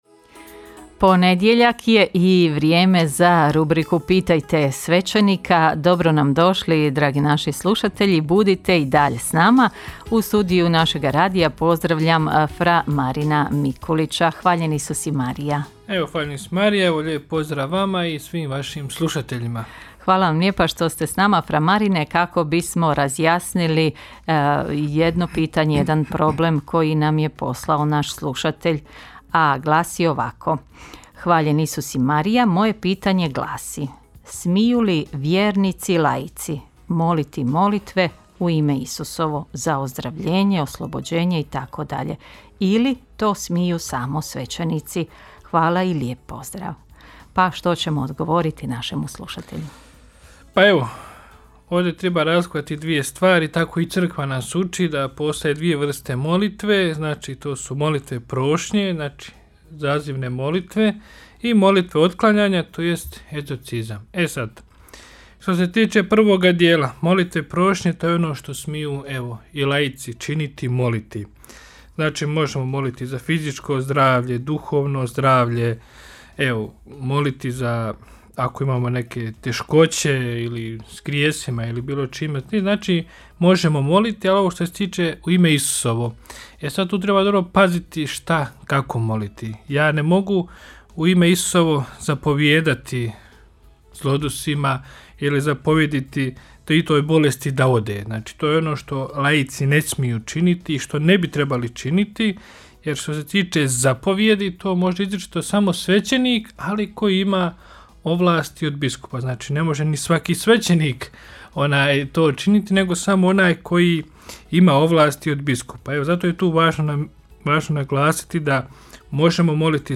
Rubrika ‘Pitajte svećenika’ u programu Radiopostaje Mir Međugorje je ponedjeljkom od 8 sati i 20 minuta, te u reprizi ponedjeljkom navečer u 20 sati i 15 minuta. U njoj na pitanja slušatelja odgovaraju svećenici, suradnici Radiopostaje Mir Međugorje.